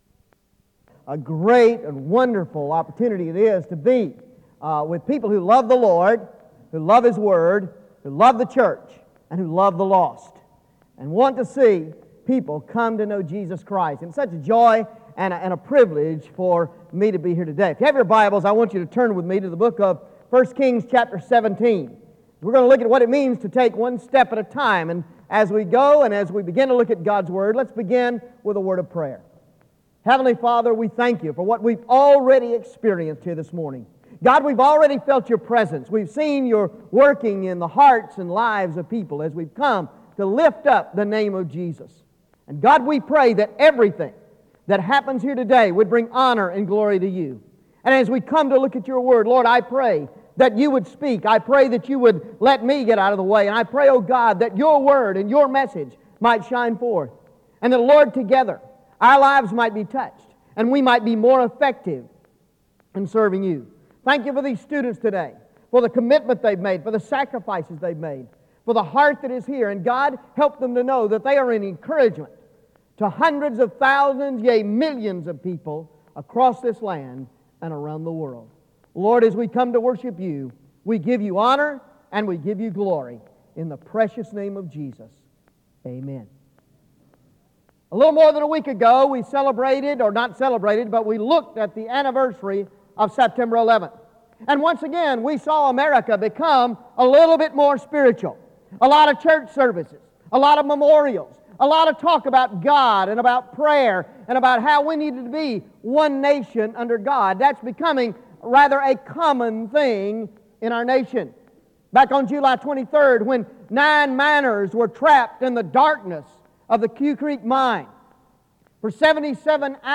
SEBTS Chapel and Special Event Recordings - 2000s